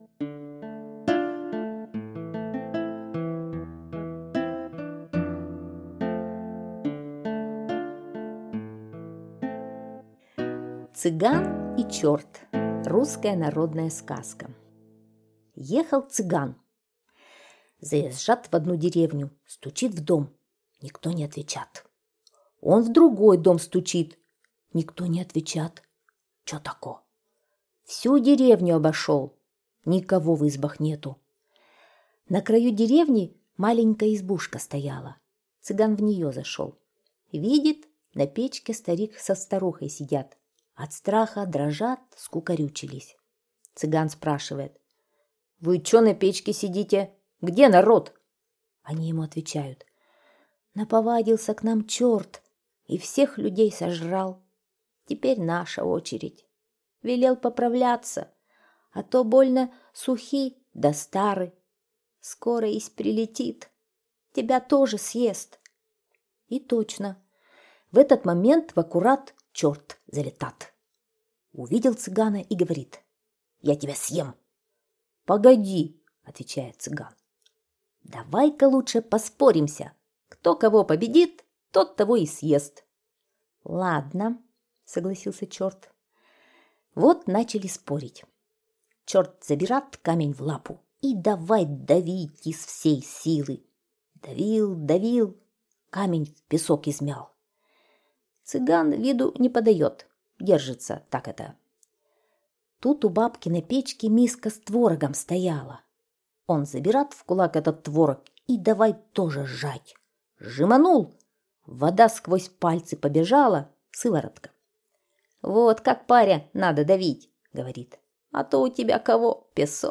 Цыган и черт - русская народная аудиосказка - слушать онлайн